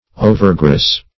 Meaning of overgross. overgross synonyms, pronunciation, spelling and more from Free Dictionary.
overgross - definition of overgross - synonyms, pronunciation, spelling from Free Dictionary Search Result for " overgross" : The Collaborative International Dictionary of English v.0.48: Overgross \O"ver*gross"\, a. Too gross.